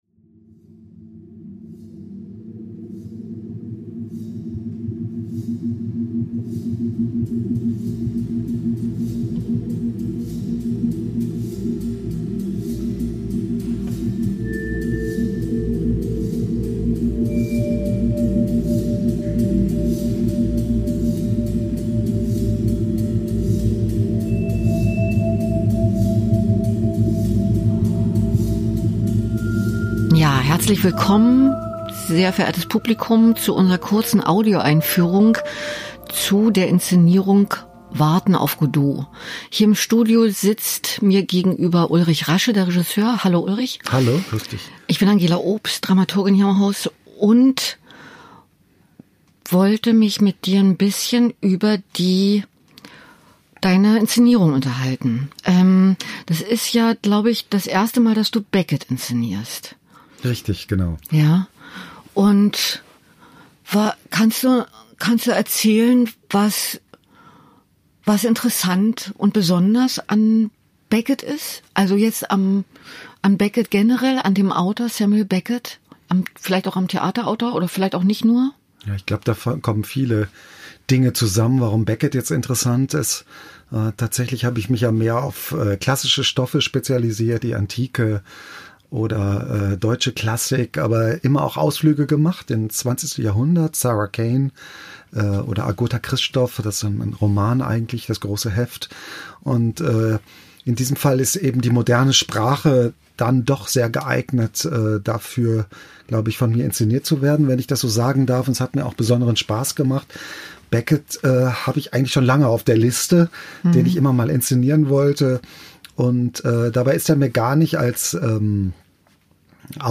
Erfahren Sie mehr über neue Inszenierungen aus dem Schauspielhaus Bochum in der neuen Episode der Talkreihe und Audioeinführung mit Künstler*innen und Dramaturg*innen der Produktion.